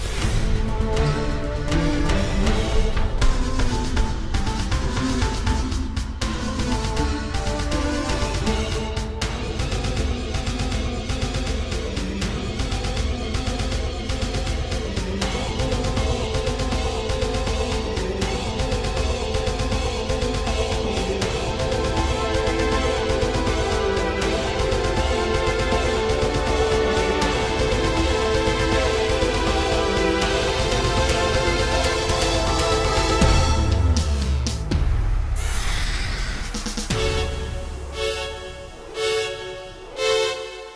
Category: Tamil Ringtones